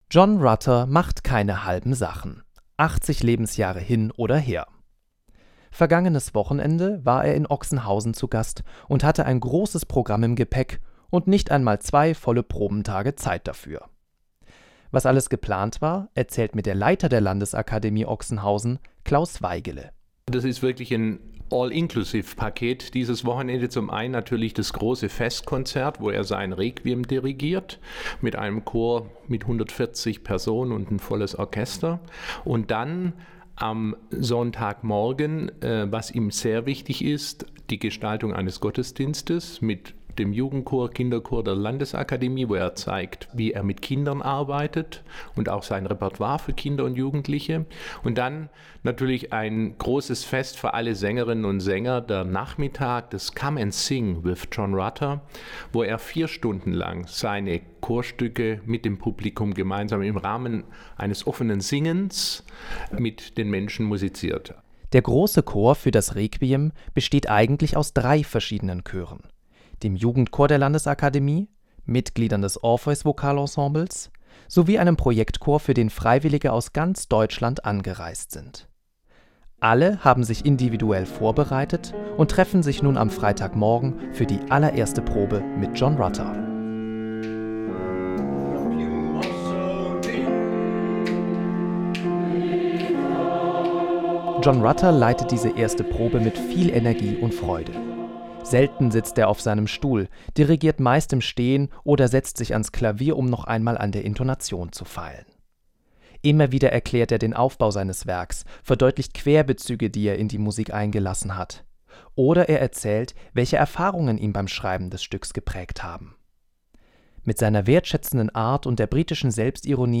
Dort leitete John Rutter ein großes Chorkonzert mit seinem „Requiem“ sowie ein Come-and-Sing – also ein Konzert, bei dem alle Interessierten eingeladen waren, gemeinsam mit ihm zu singen.